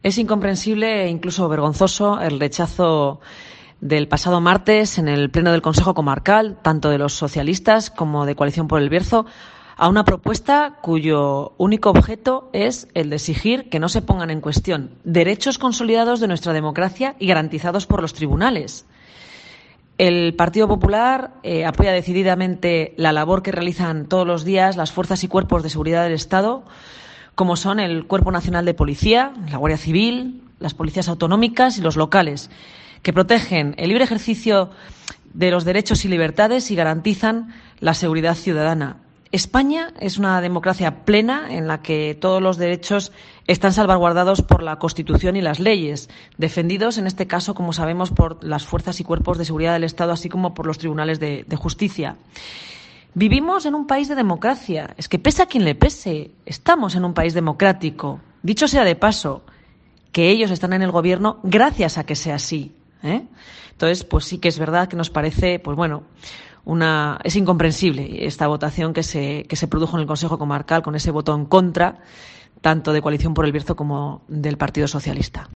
AUDIO: Escucha aquí a Rosa Luna Fernández, portavoz de los populares en el Consejo Comarcal del Bierzo